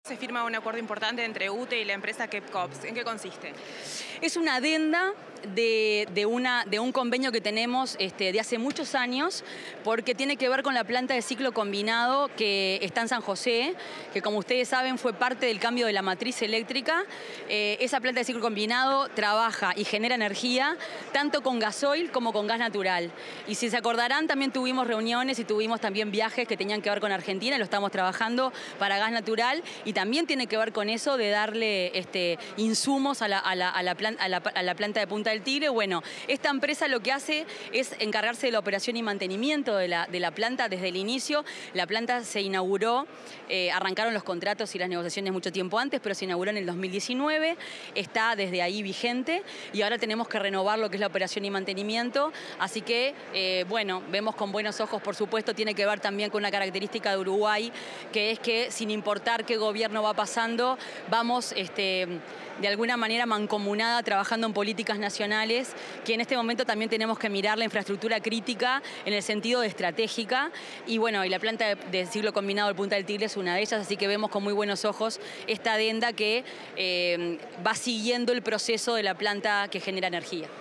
Declaraciones de la ministra de Industria, Fernanda Cardona
Declaraciones de la ministra de Industria, Fernanda Cardona 21/10/2025 Compartir Facebook X Copiar enlace WhatsApp LinkedIn Durante la celebración del 113.° aniversario de UTE, la ministra de Industria, Energía y Minería, Fernanda Cardona, informó sobre la firma de una extensión del contrato para operar la central térmica Punta del Tigre.